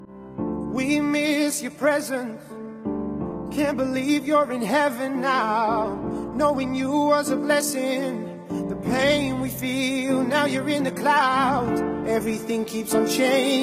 soulful and soothing track